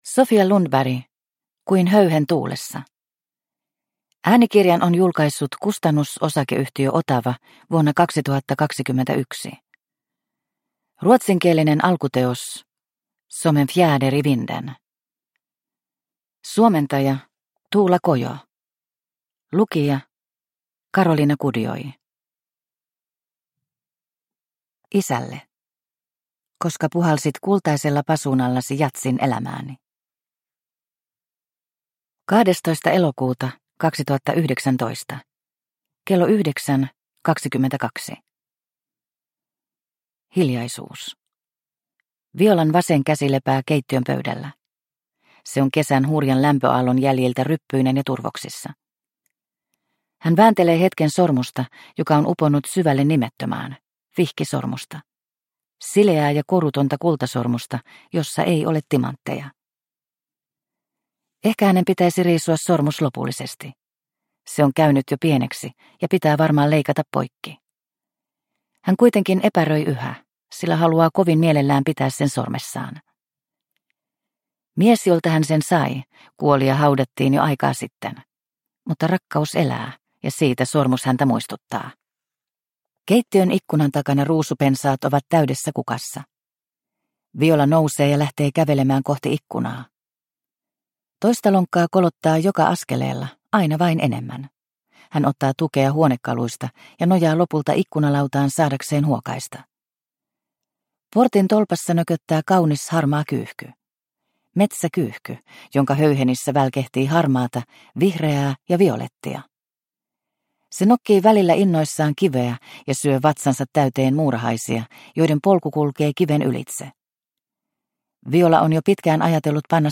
Kuin höyhen tuulessa – Ljudbok – Laddas ner